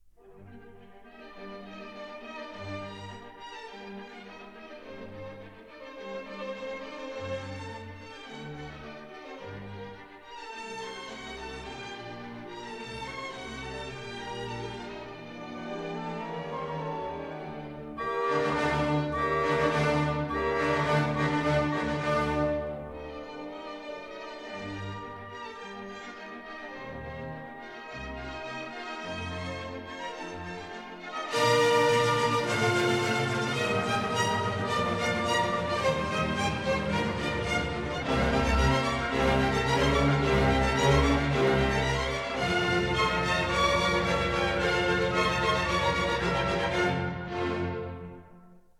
Recorded in the Sofensaal, Vienna on 28 May 1959.